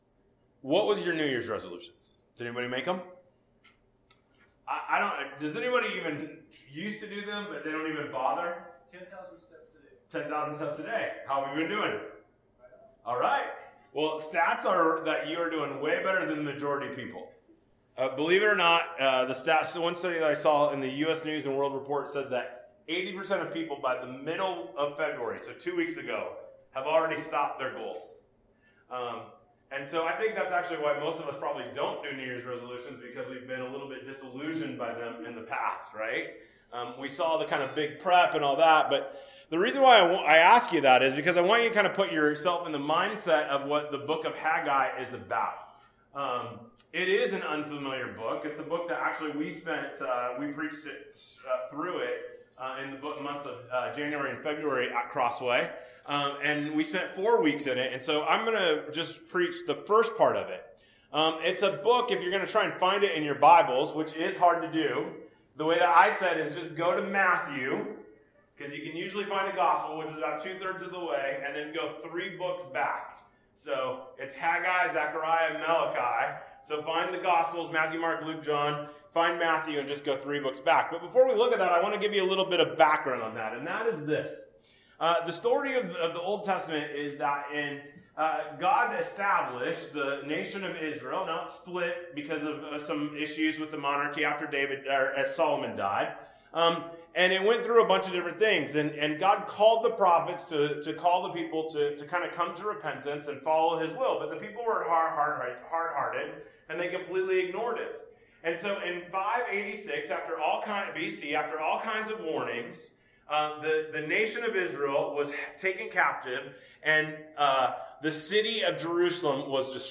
Bible Text: Haggai 1 | Preacher